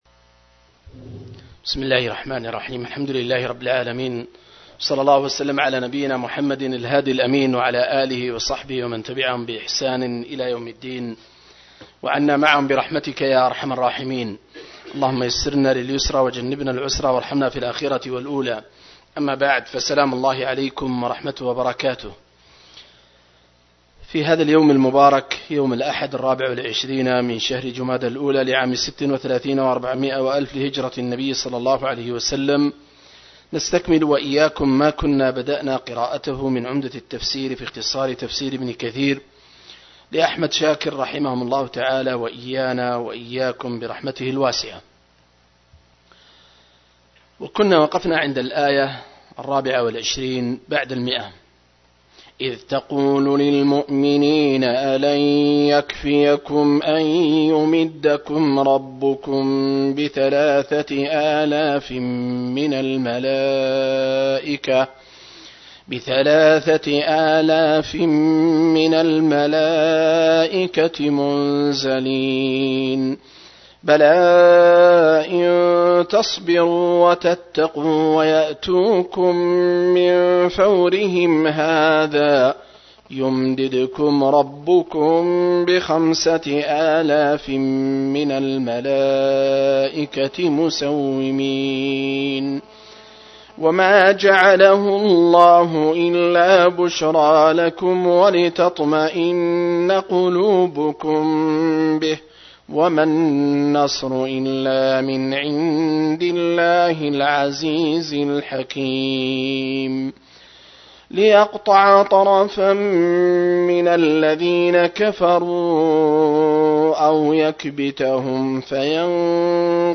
073- عمدة التفسير عن الحافظ ابن كثير رحمه الله للعلامة أحمد شاكر رحمه الله – قراءة وتعليق –